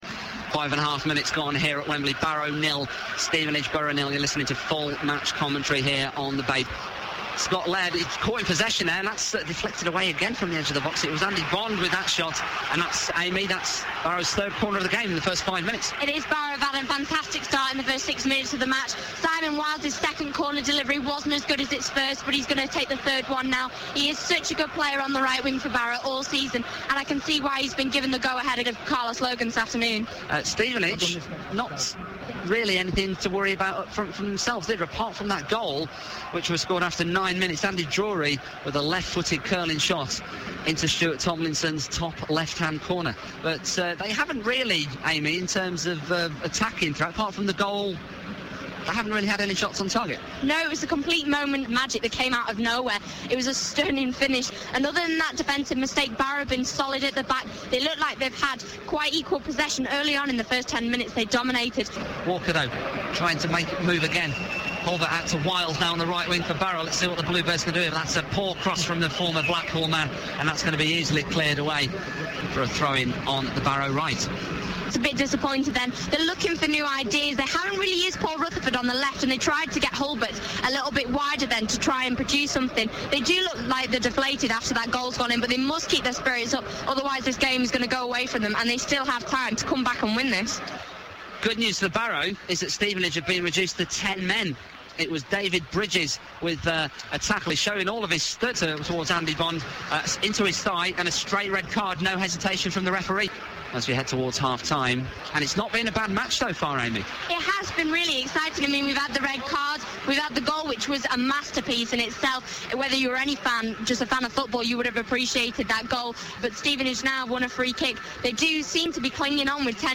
The edited highlights of the FA Trophy Final 2010 featuring Barrow v Stevenage at Wembley Stadium. It was a memorable 2-1 victory for Barrow over the 2010 Blue Square Premier Champions Stevenage in a match with two red cards.